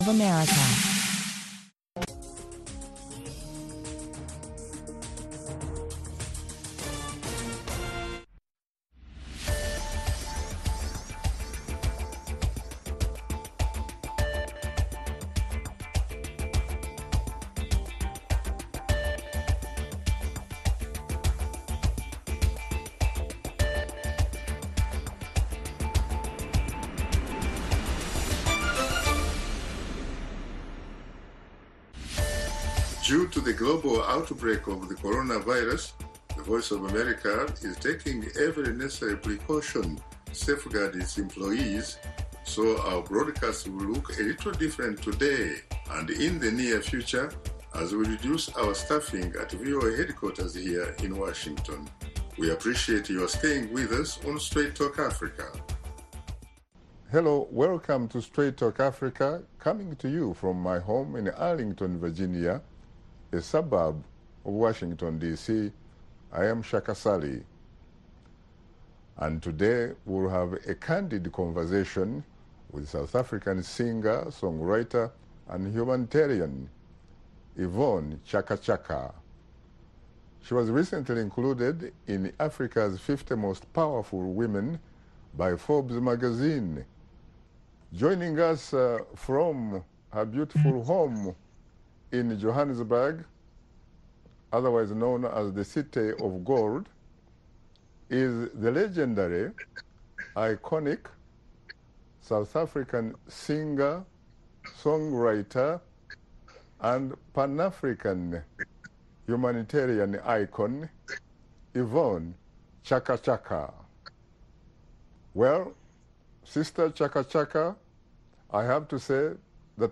Join veteran journalist Shaka Ssali on Straight Talk Africa every Wednesday as he and his guests discuss topics of special interest to Africans, including politics, economic development, press freedom, health, social issues and conflict resolution.